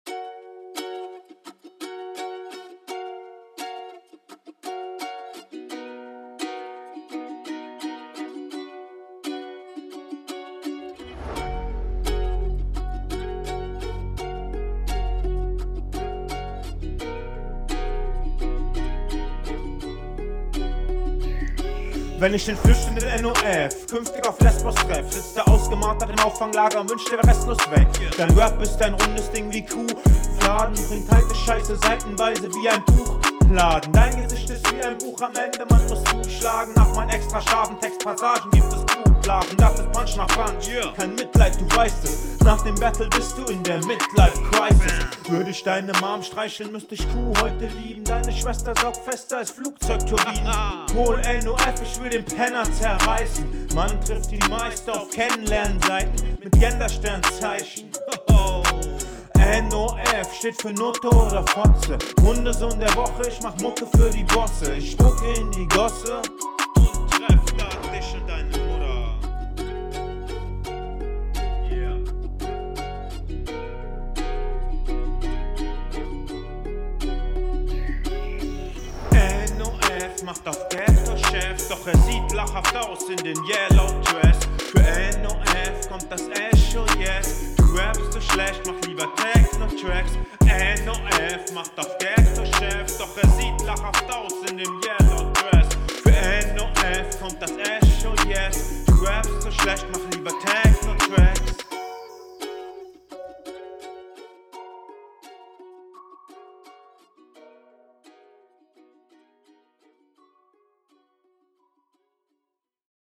erst mal muss ich wieder schimpfen wegen der verständlichkeit. bisschen lautere vocal spur wäre angebracht.